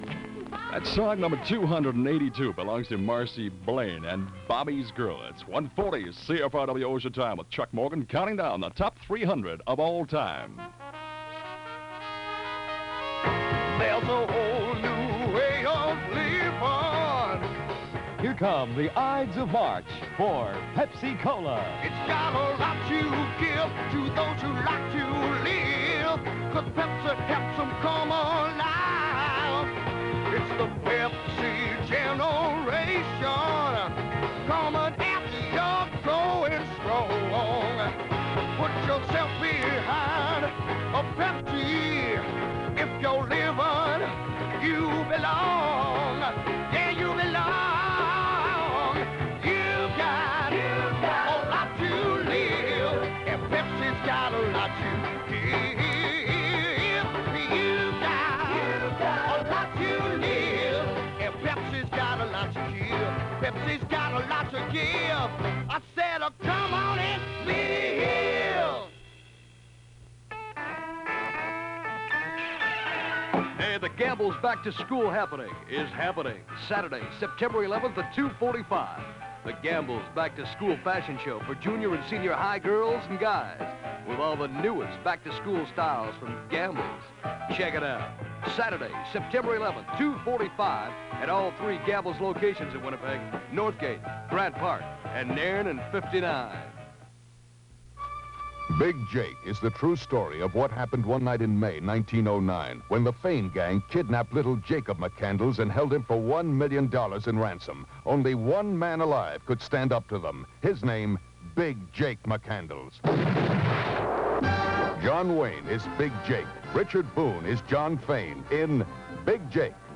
Winnipeg Radio in 1971